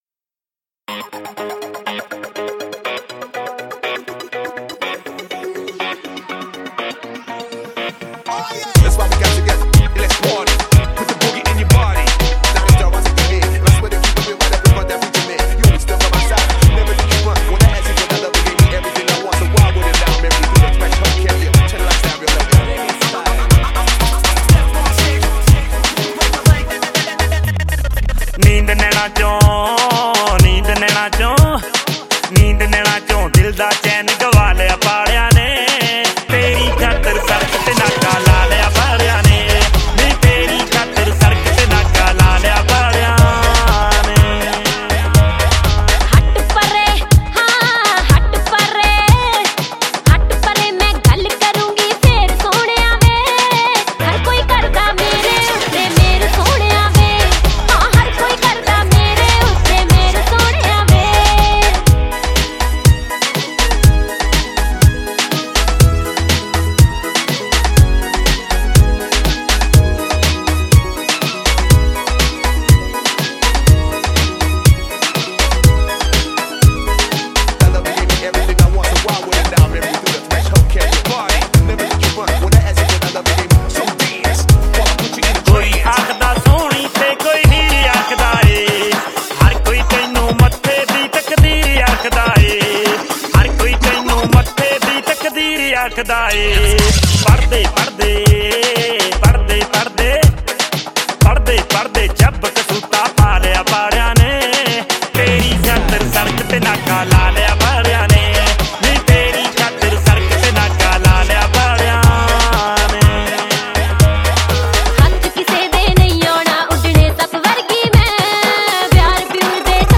Punjabi Bhangra MP3 Songs
Happy Mood